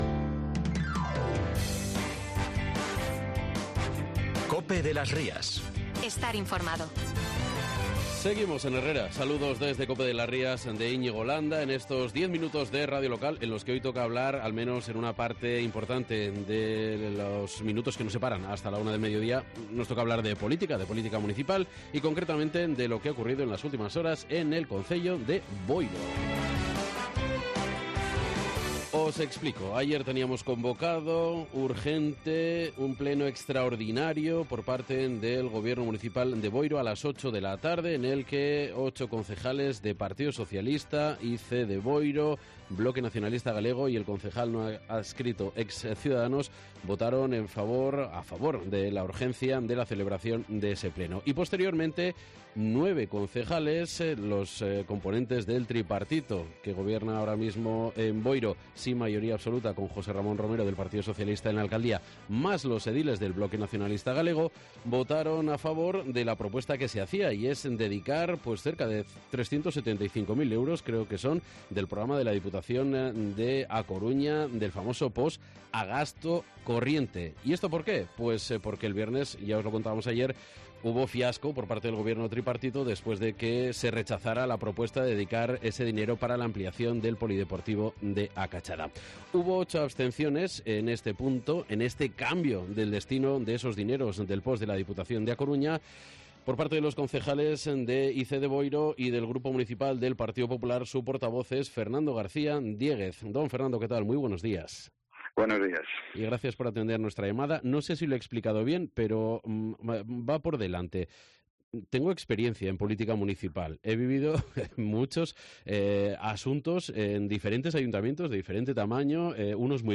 Hablamos con el portavoz del Partido Popular en Boiro, Fernando García Diéguez, sobre este asunto y la situación política y financiera de la administración municipal a apenas 8 meses del final de la legislatura.